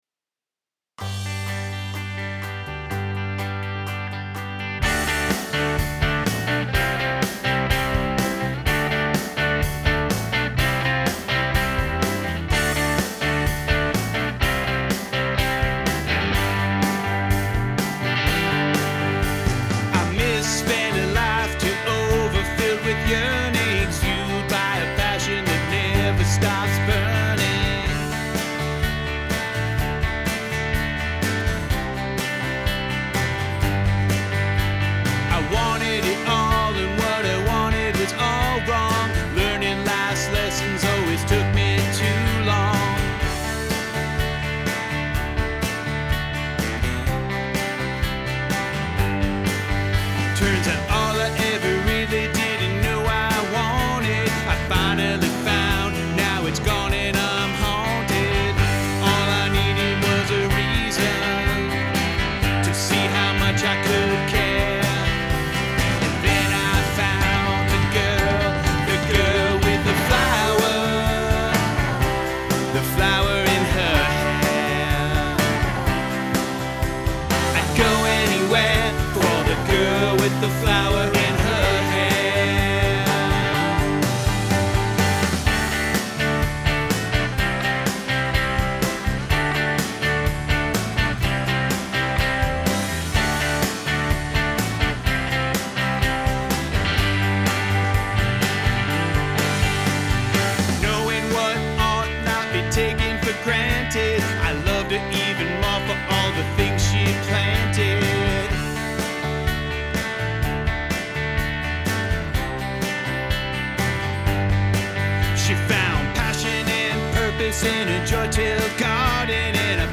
These are our demos — we recorded it all ourselves at home.